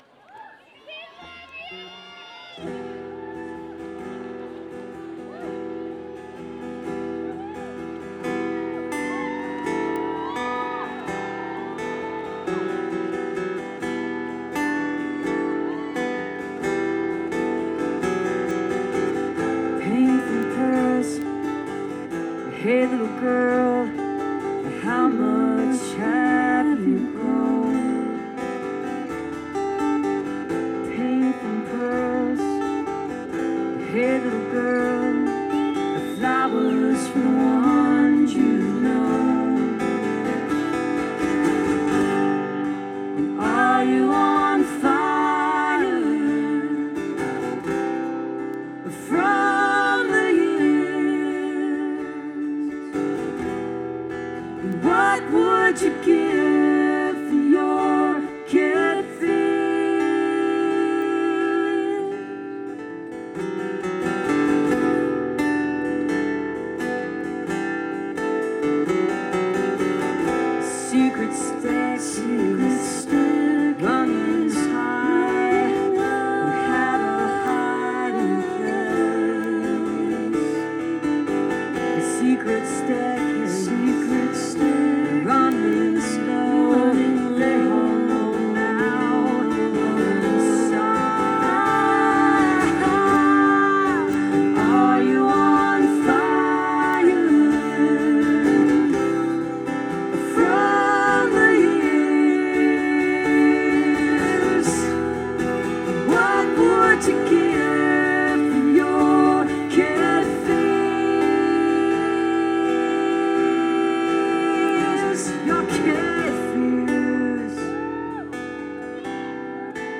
(acoustic duo show)